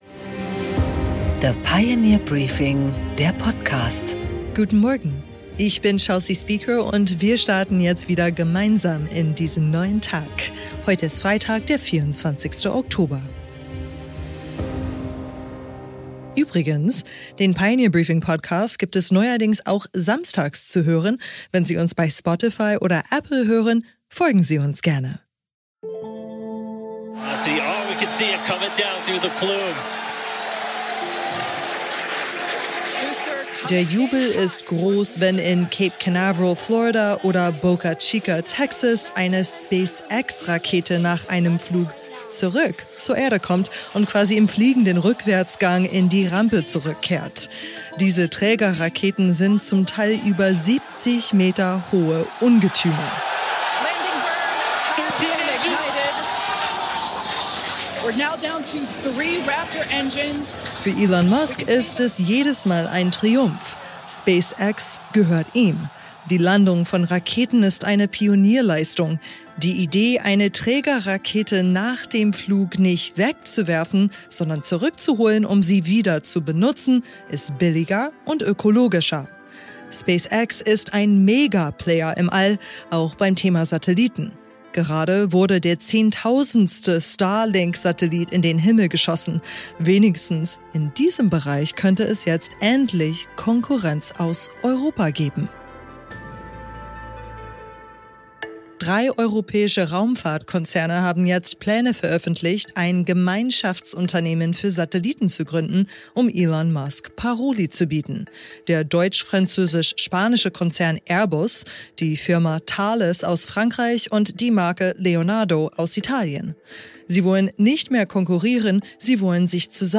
Interview mit Sigmar Gabriel